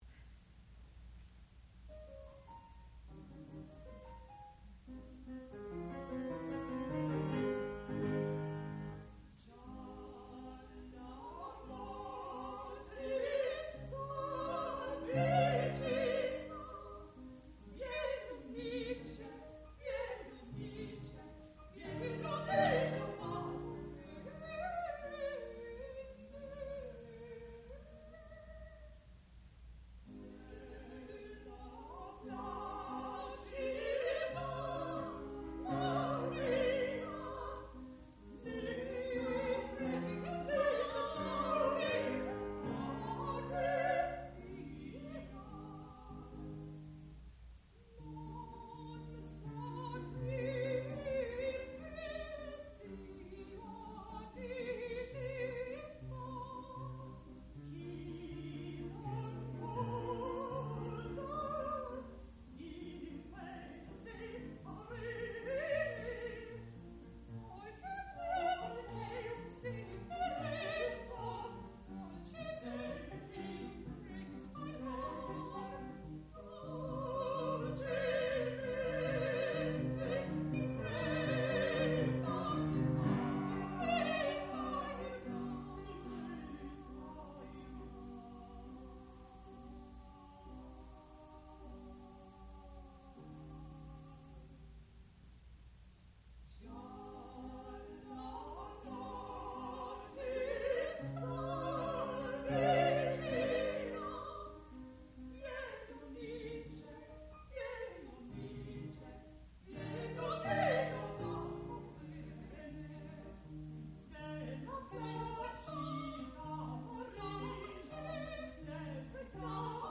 sound recording-musical
classical music
mezzo-soprano
piano
Graduate Recital